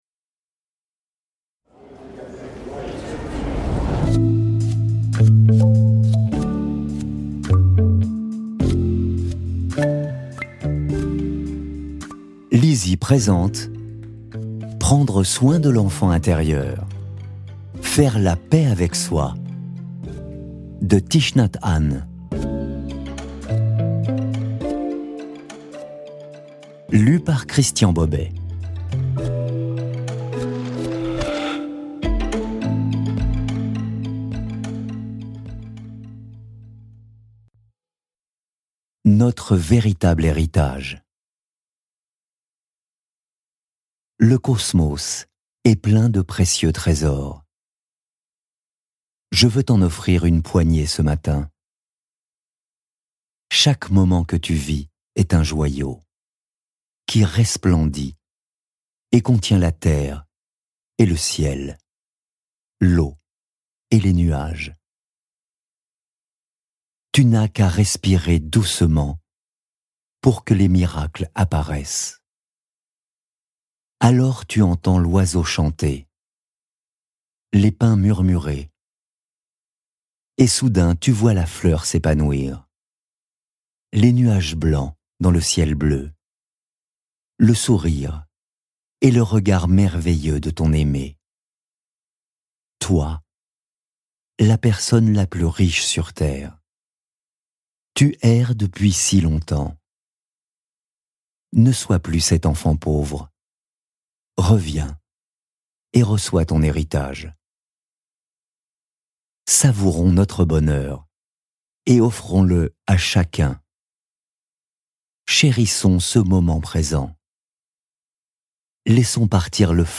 Click for an excerpt - Prendre soin de l'enfant intérieur de Thich Nhat Hanh